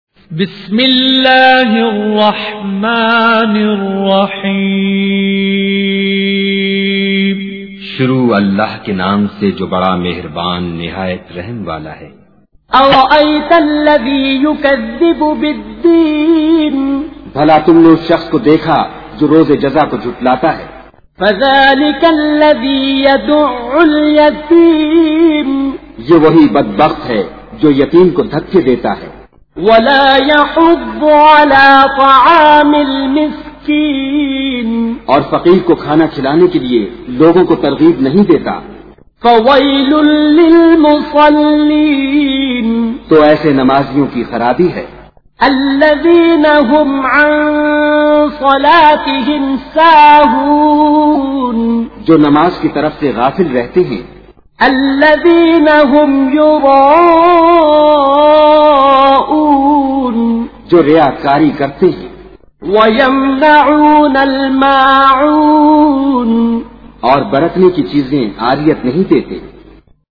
تلاوت بااردو ترجمہ